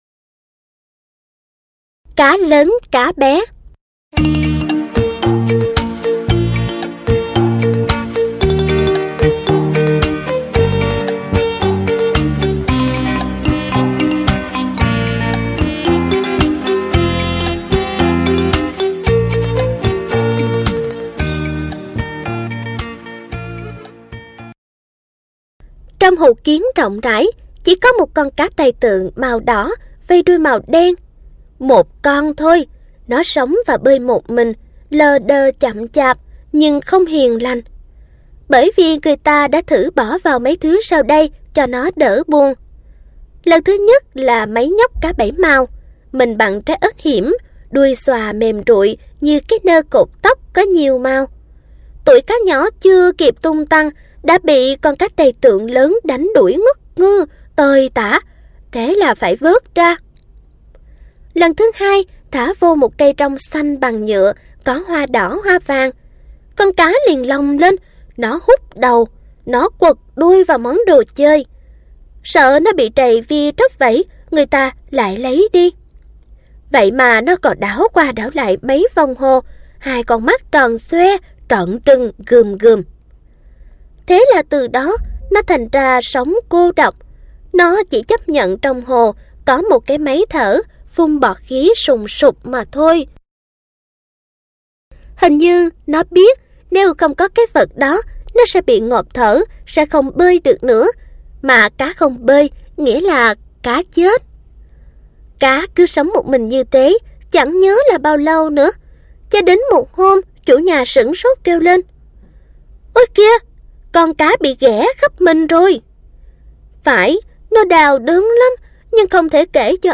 Sách nói | Xóm đồ chời